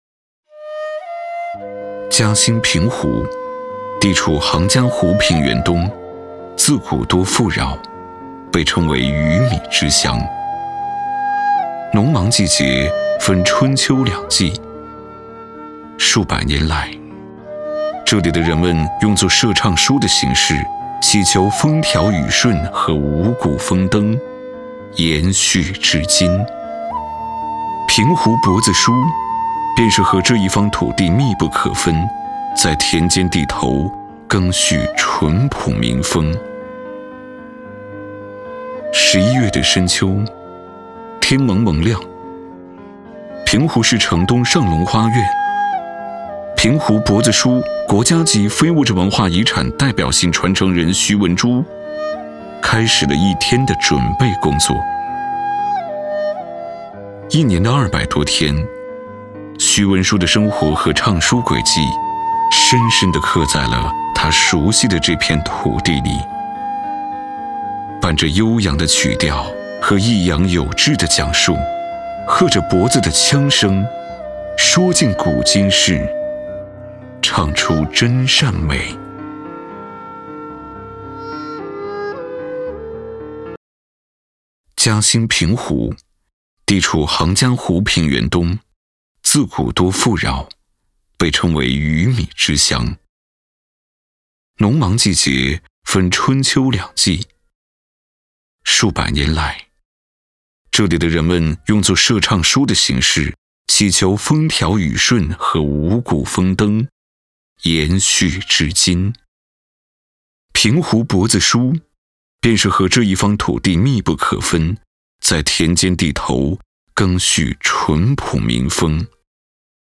沉稳大气,时尚宣传
国语配音
男635-宣传片-嘉兴平湖鱼米之乡.mp3